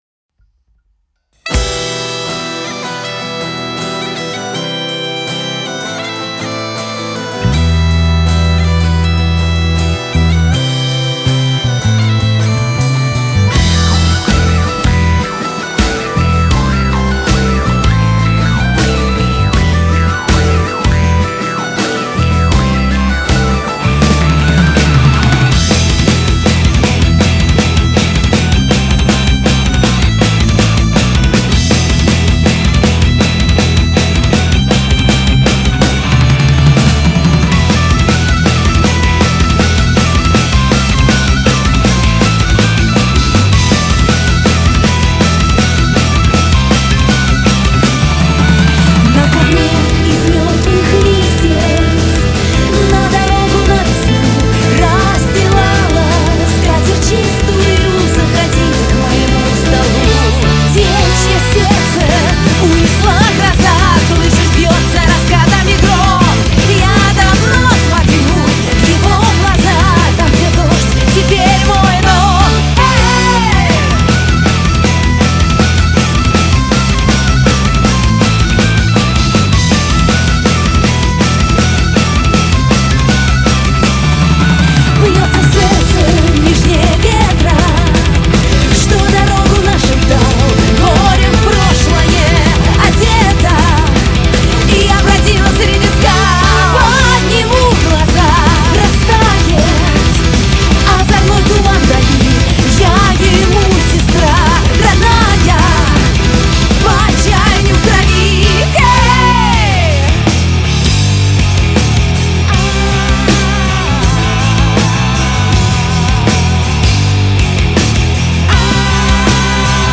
без мастеринга